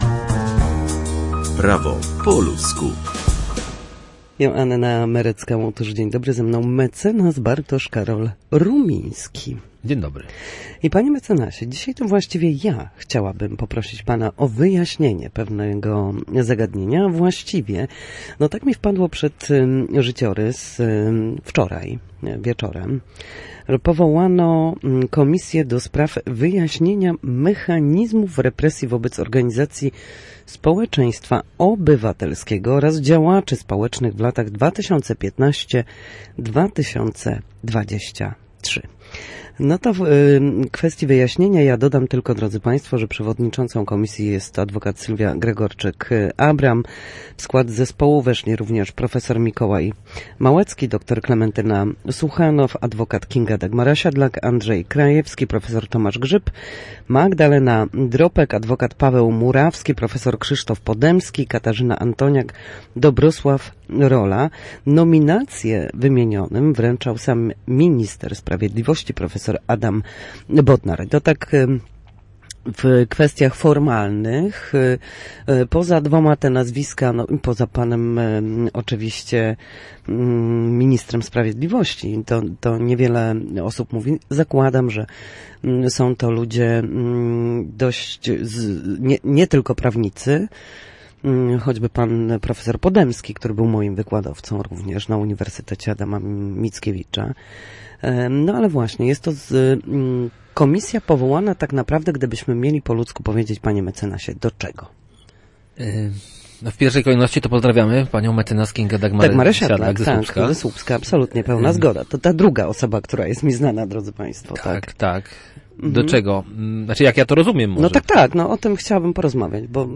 W każdy wtorek o godzinie 13:40 na antenie Studia Słupsk przybliżamy meandry prawa. Nasi goście – prawnicy i eksperci – odpowiadają na jedno pytanie dotyczące zachowania w sądzie lub podstawowych zagadnień prawnych.